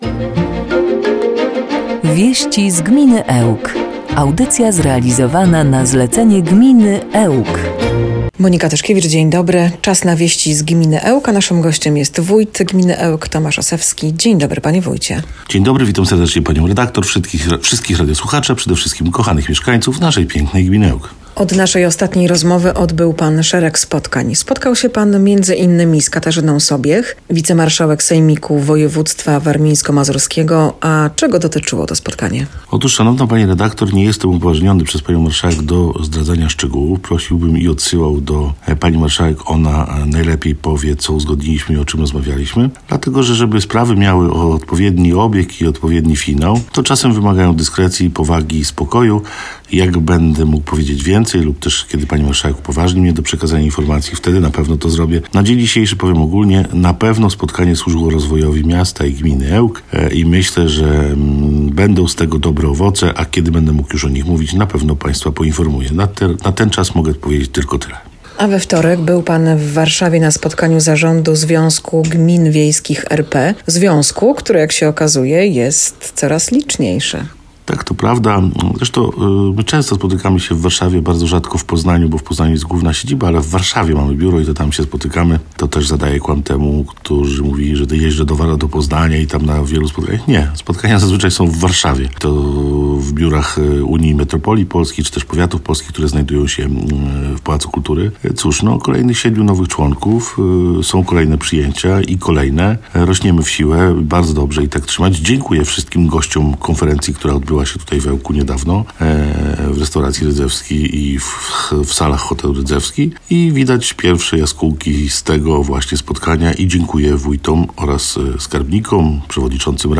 Gościem Radia 5 był wójt Tomasz Osewski.
21-02-WIESCI-Z-GMINY-ELK-z-jinglami.mp3